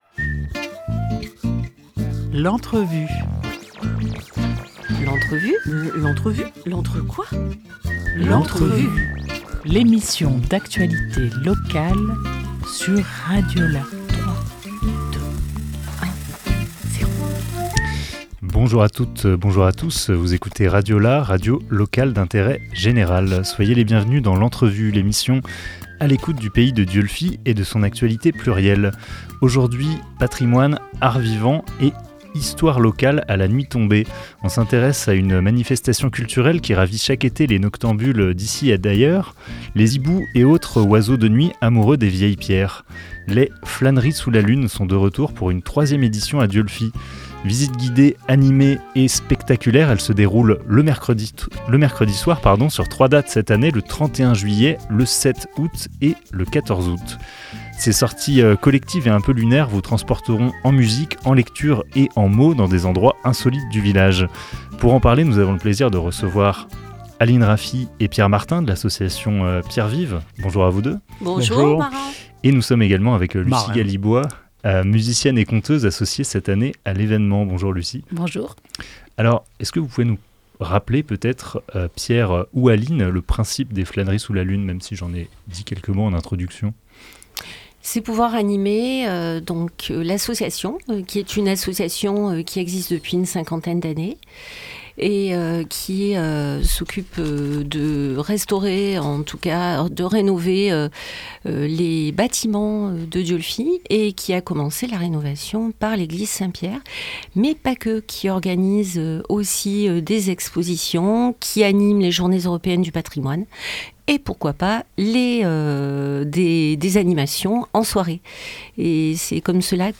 25 juillet 2024 11:12 | Interview